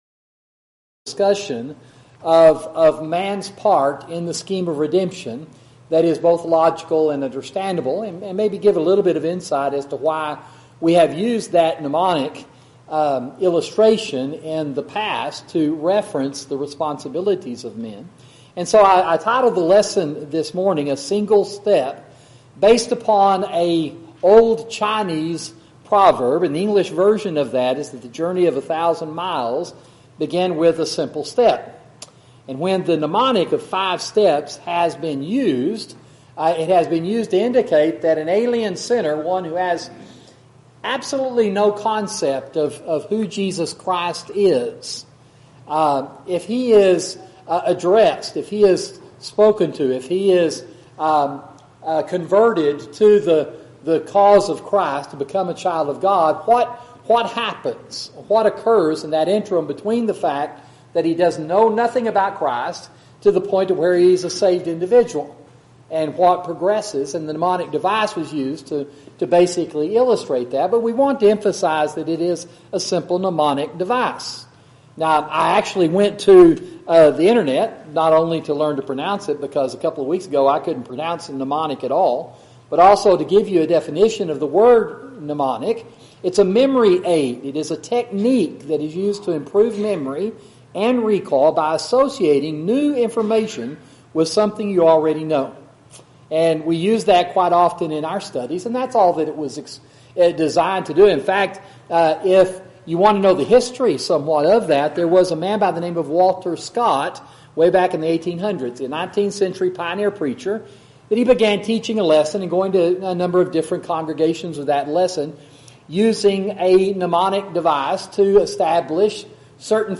Audio PPTX (Right Click to SAVE) YouTube Video of Sermon